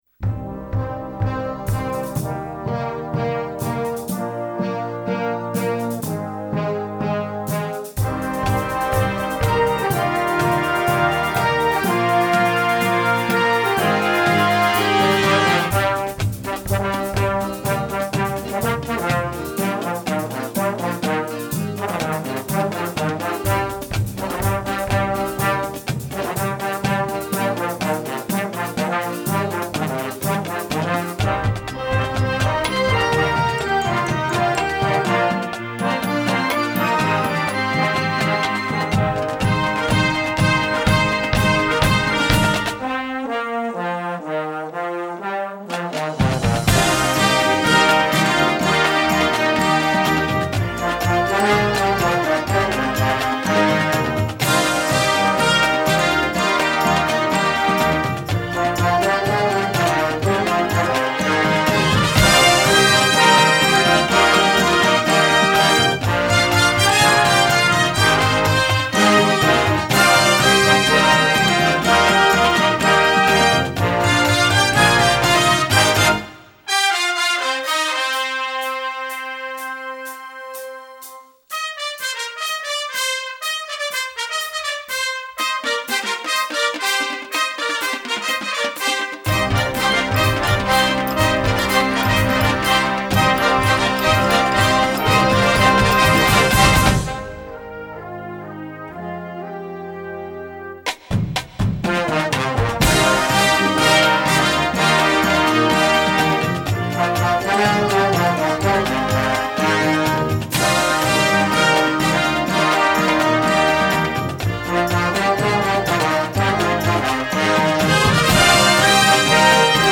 Gattung: Marching Band Series
Besetzung: Blasorchester